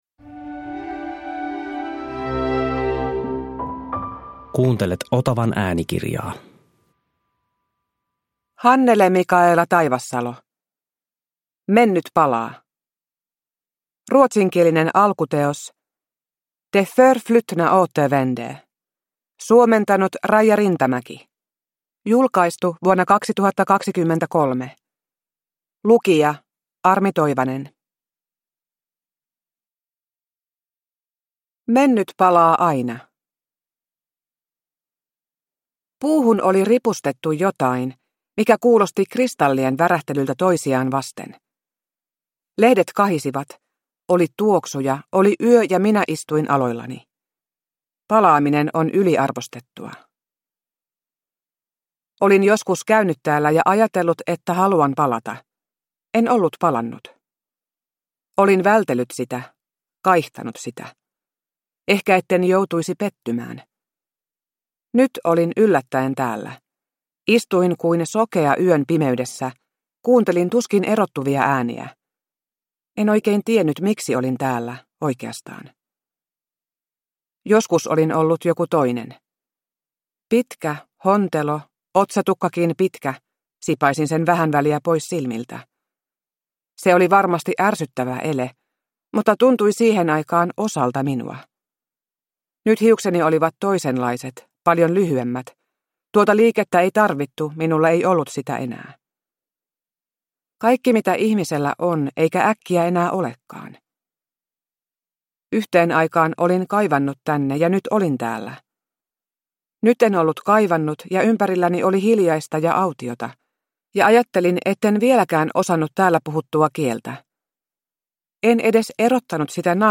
Mennyt palaa – Ljudbok – Laddas ner
Uppläsare: Armi Toivanen